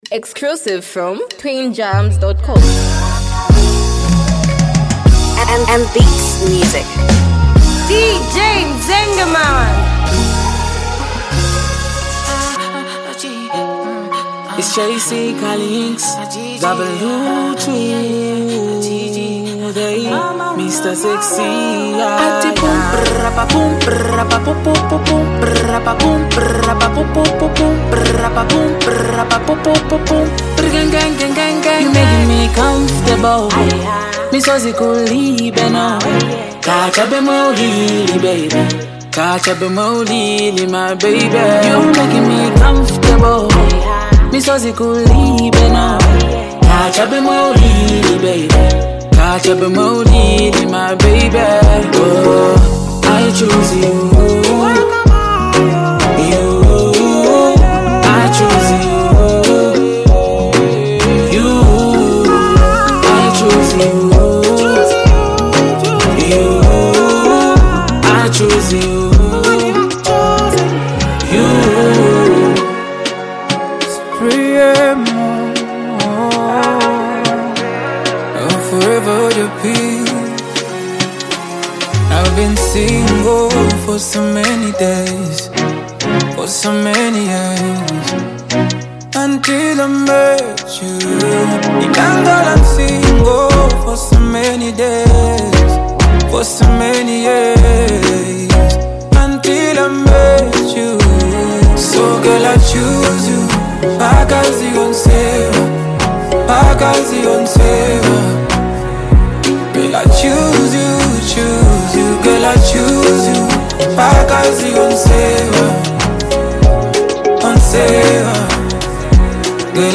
hype, street vibes, and a powerful hook with unique flow